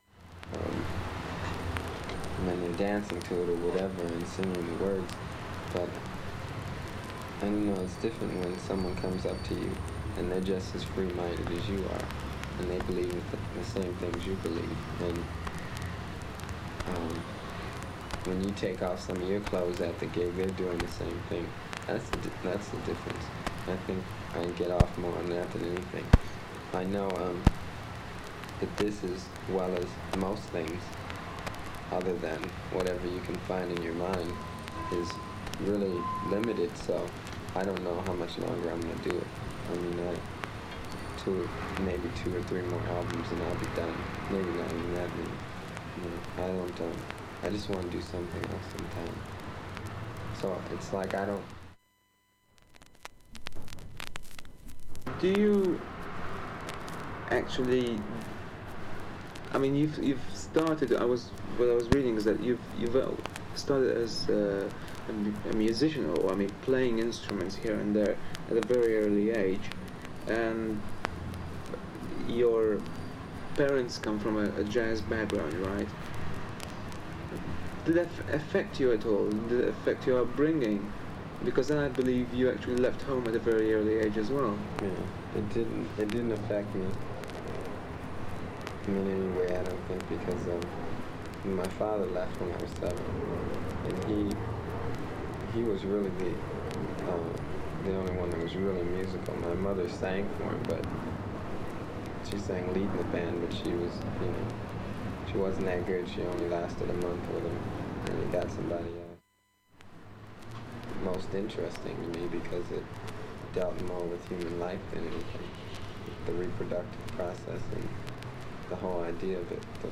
若干揺れボツ音が入りますが、再生出来ました。
ほか数面の数か所にスレなどでチリプツ入る箇所あります。